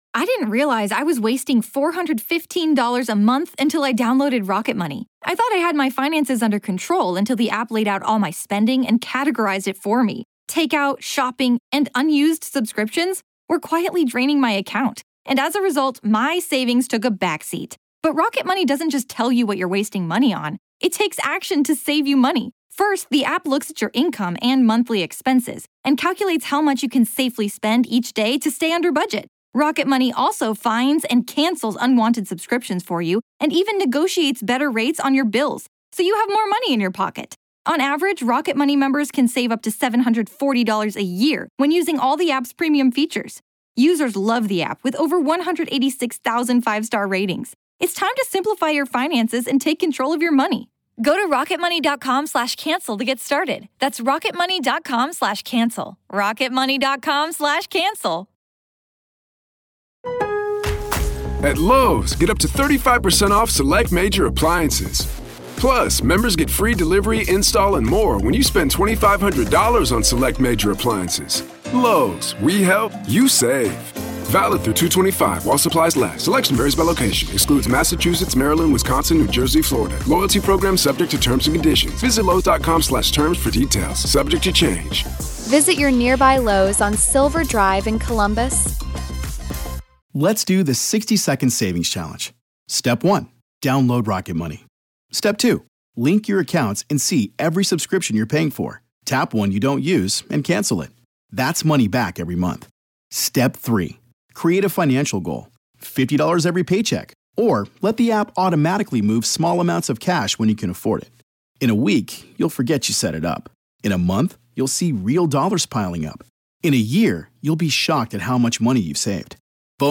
an attorney and former federal prosecutor